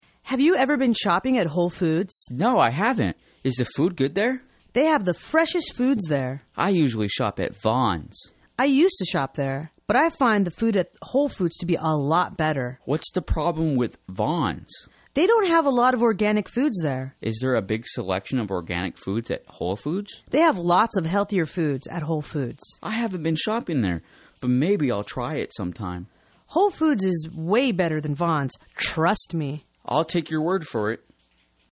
英语情景对话：Comparing Foods from Different Stores(3) 听力文件下载—在线英语听力室